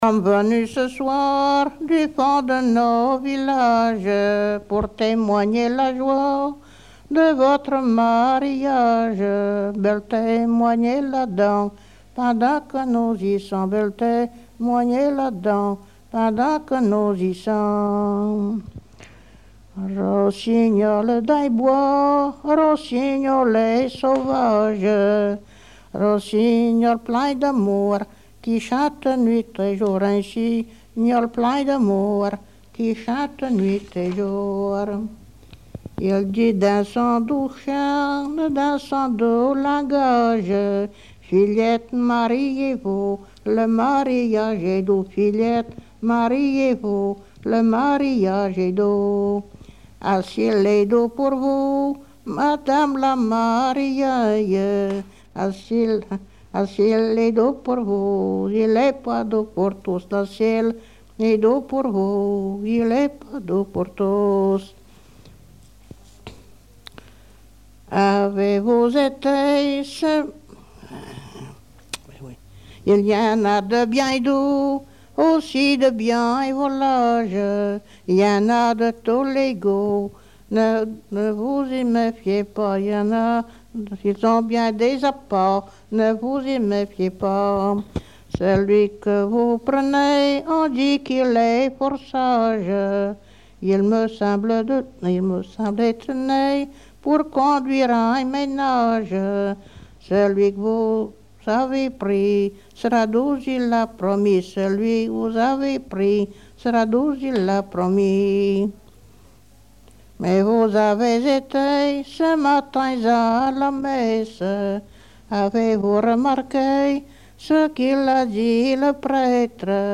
circonstance : fiançaille, noce ;
Genre strophique
Chansons traditionnelles
Pièce musicale inédite